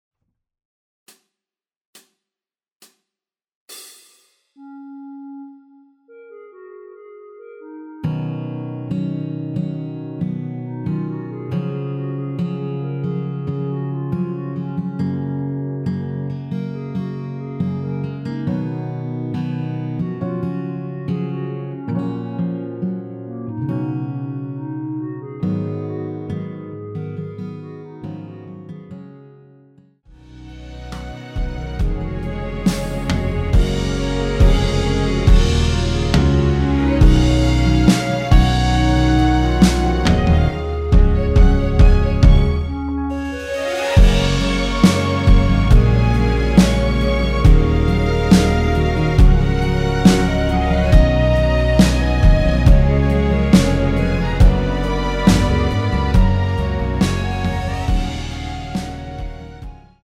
전주 없이 시작하는 곡이라서 시작 카운트 만들어놓았습니다.(미리듣기 확인)
원키에서(-2)내린 멜로디 포함된 MR입니다.
Gb
앞부분30초, 뒷부분30초씩 편집해서 올려 드리고 있습니다.